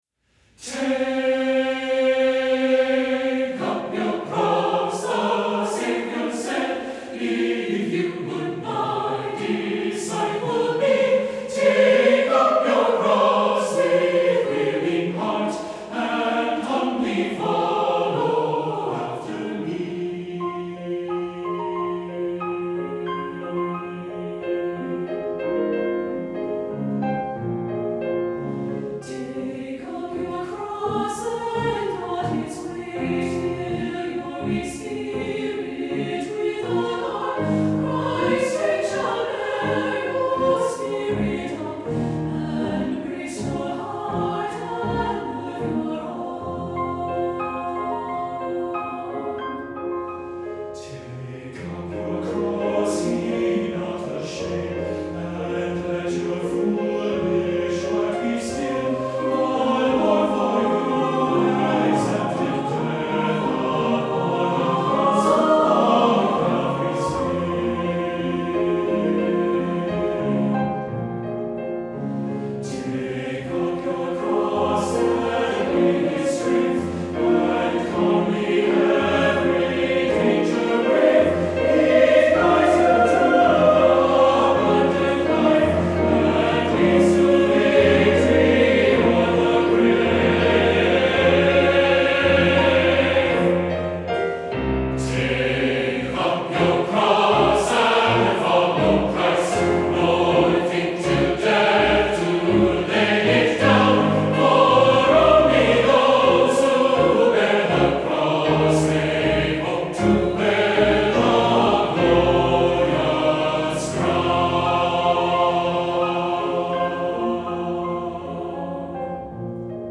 Voicing: SA(T)B